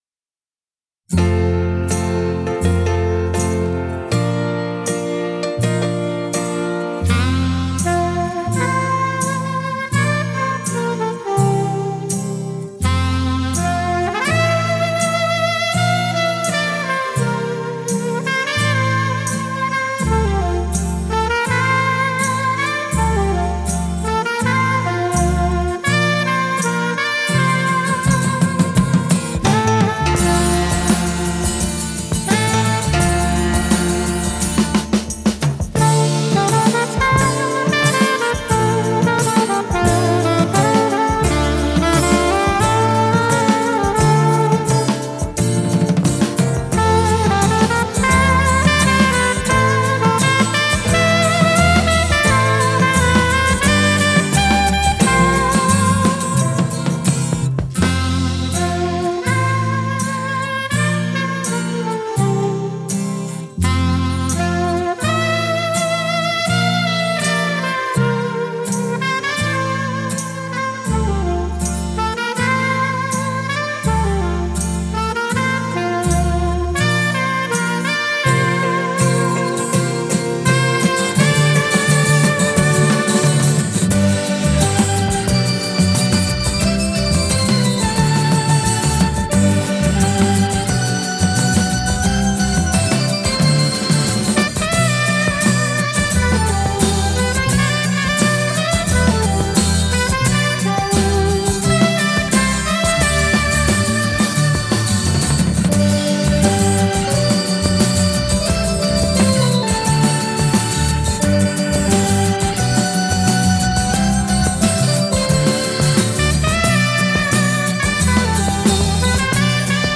Жанр: Easy Listening/Instrumental